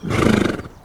horseman_select5.wav